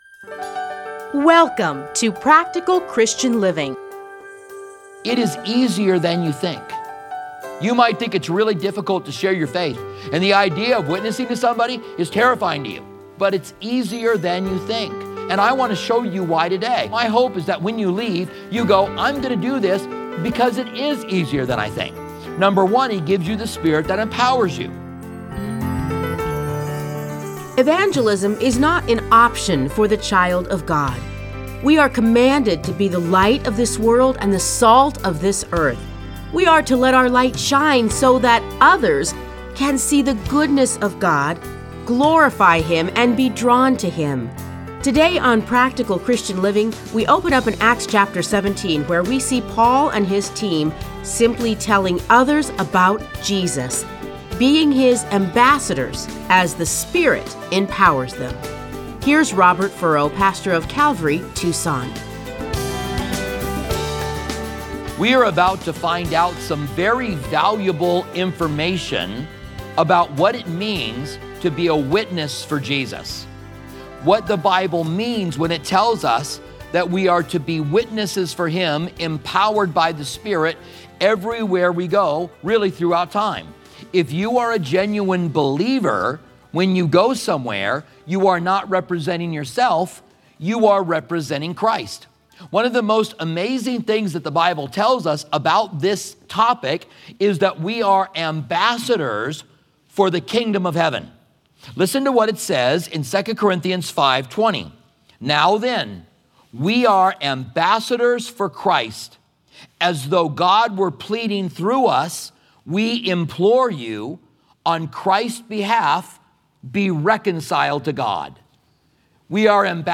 Listen to a teaching from Acts 17:1-4.